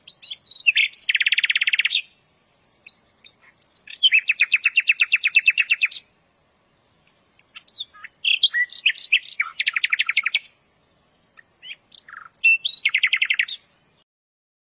夜莺叫声夜歌鸲鸟鸣声